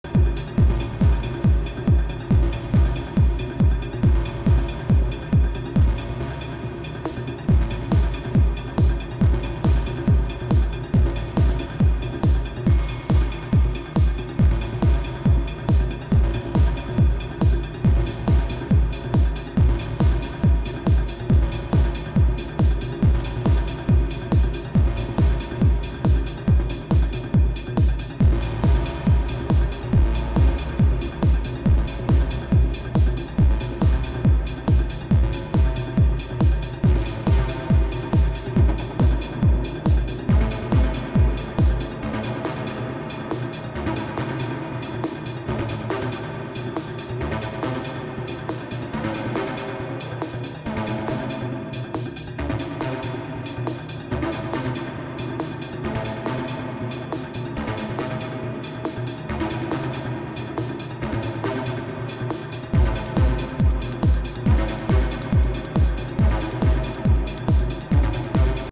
To be honest, sometimes it’s worse, but here’s a bunch of snippets I just recorded, taken from my last improv practice session.
Hearing it back it’s less worse then I sometimes imagine, but it does have this barrage of short staccato 16ths constantly.